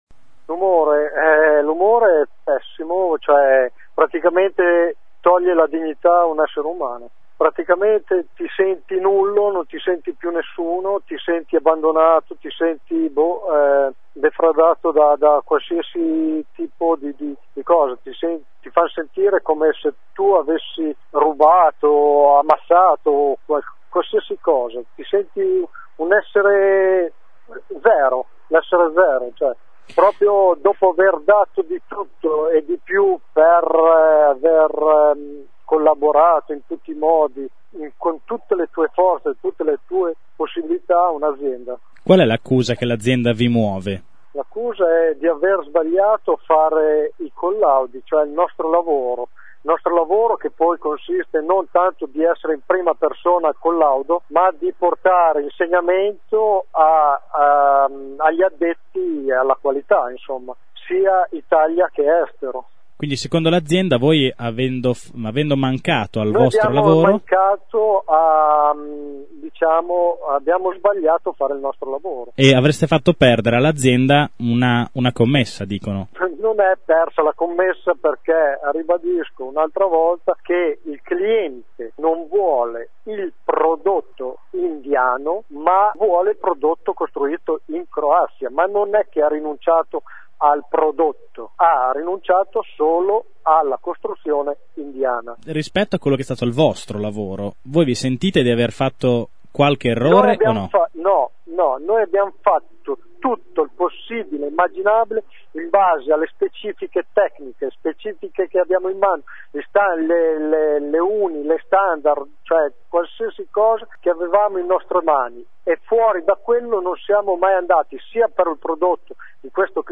Ascolta la testimonianza di uno dei lavoratori licenziati